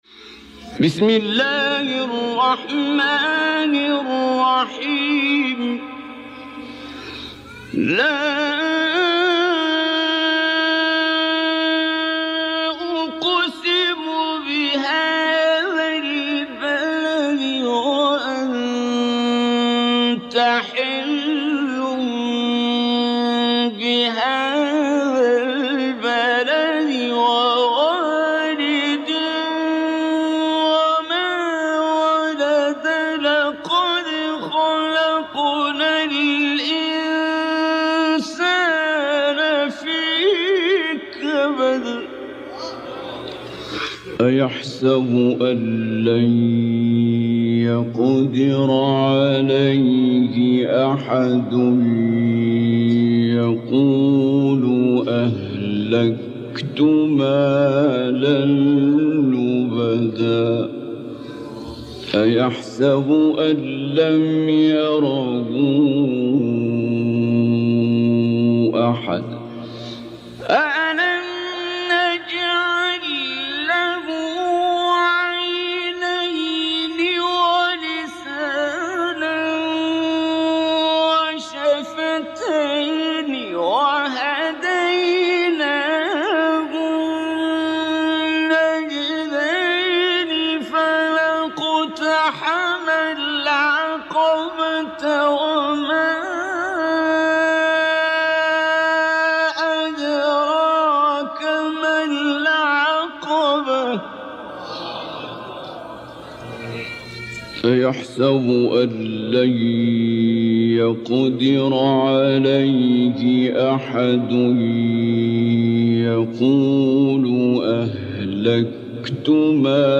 فعالیت 519 - (همنوا : همخوانی قرآن کریم - سوره بلد)
سوره بلد-عبدالباسط.mp3